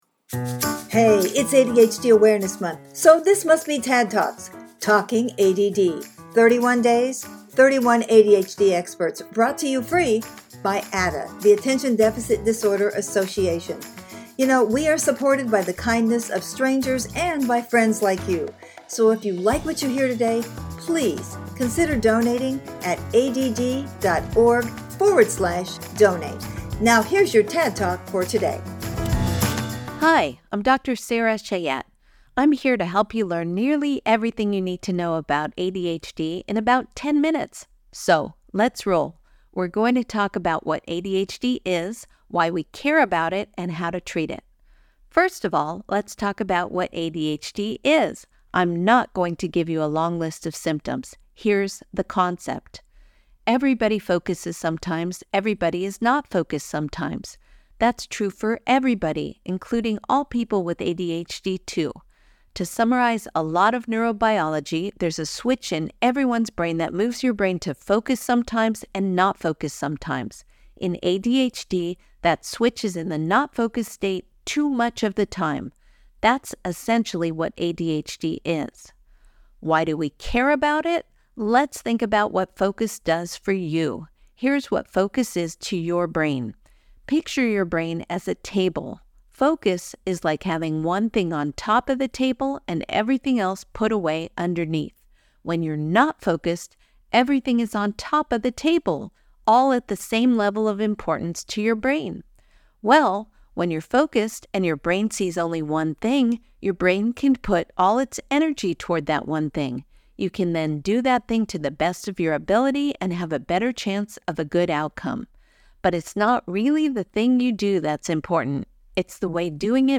I recently gave an interview to ADDA in a “TADD Talk”